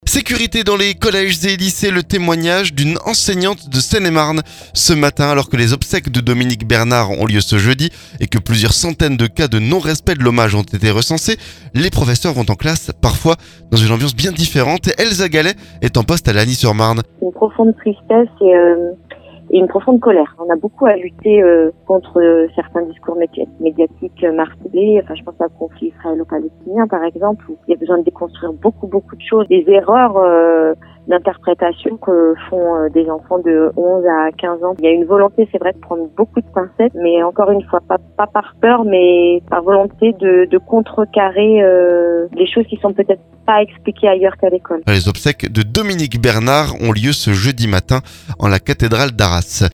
Sécurité dans les lycées : le témoignage d'une enseignante de Seine-et-Marne ce matin.